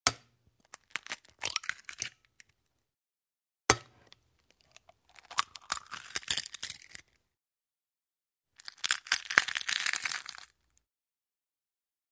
Звуки кухни, жарки
Разбивают яйцо и снимают скорлупу